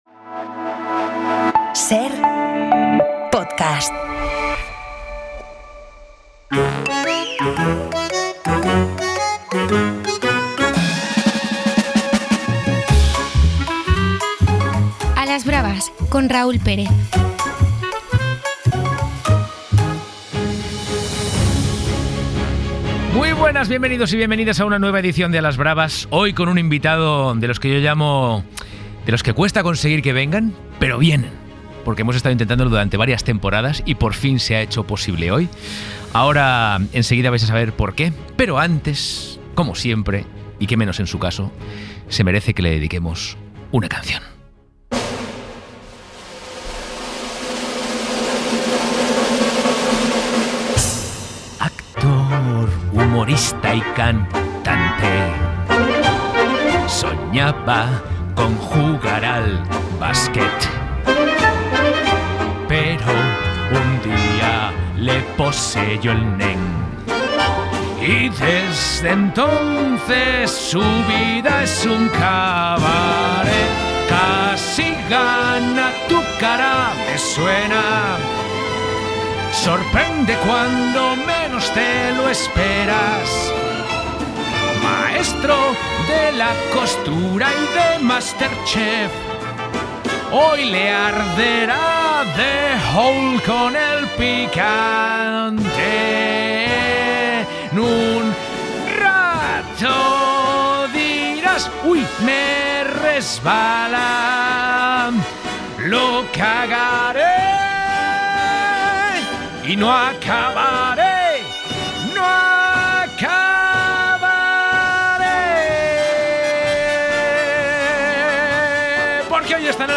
Audio de Humor en la Cadena SER en Podium Podcast
El invitado de esta semana es el actor, humorista y cantante Edu Soto, que se enfrenta a nuestro reto picante y acaba, literalmente, sin palabras. Viene a presentarnos 'El cabaret de Edu Soto' y aprovechamos para repasar su carrera: personajes míticos como 'El Neng', imitaciones y mucha televisión.